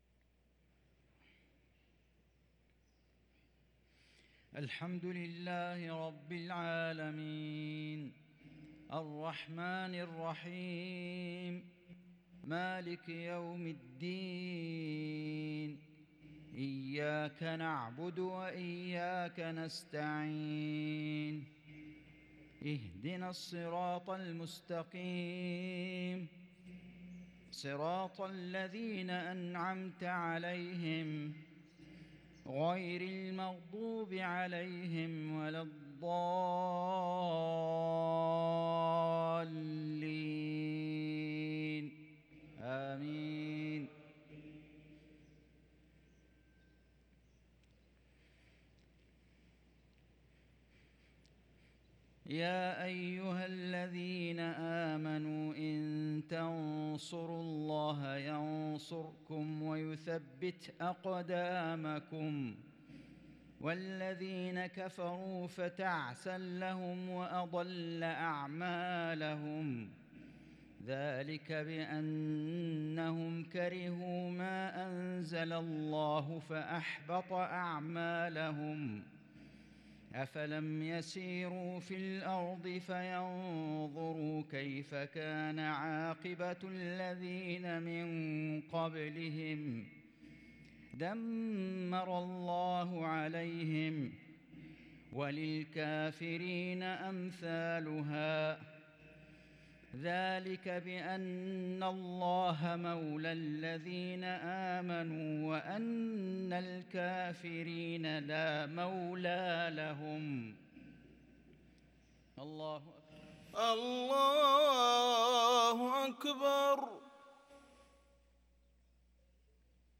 قرَّةُ العينَيْن ، بنقل صلوات وأخبار الحرمين الشريفَيْن ، لـ شهر جمادى الآخرة 1444هـ
ونظرًا لما للحرمين الشريفين من مكانة ، ولما لأئمتهما من تلاوات رائعة وأصوات ندية ؛ فإن هذا العمل يستحق الإشادة...